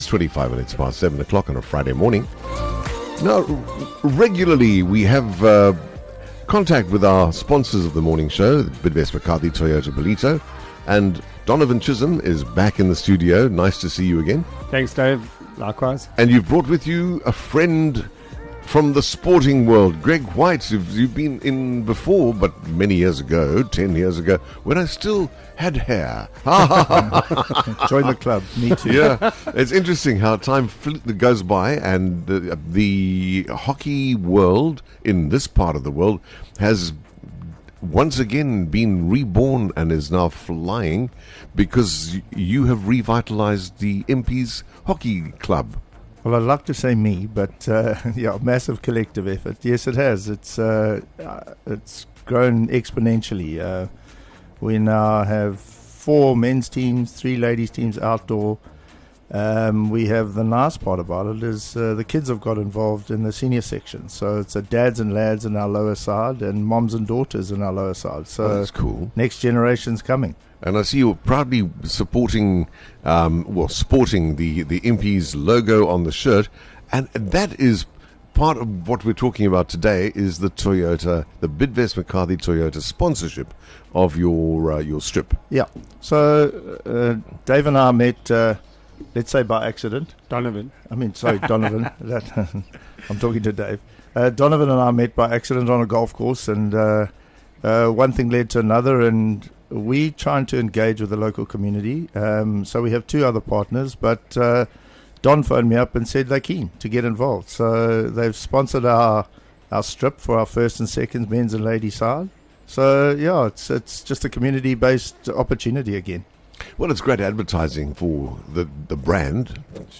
Join us for a featured chat with our valued sponsors of the Morning Show – Bidvest McCarthy Toyota Ballito, driving excellence every morning.